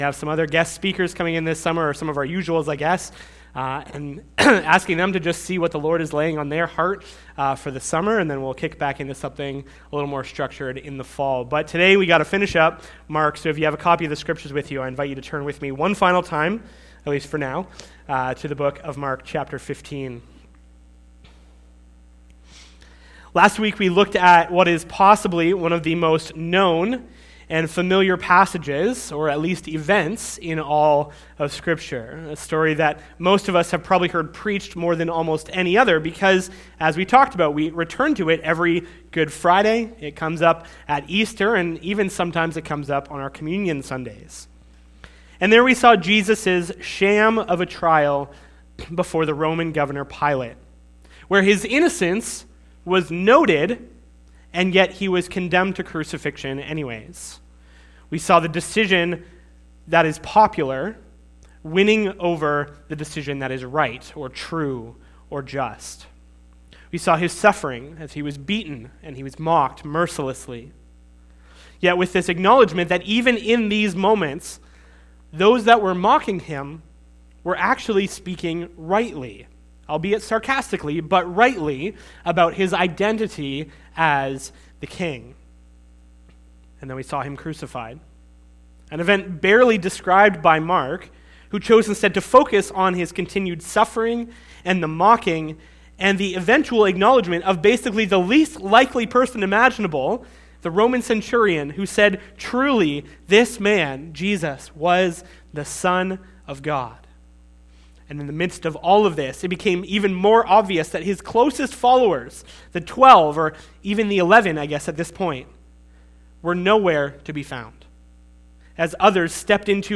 Sermon Audio and Video Now What?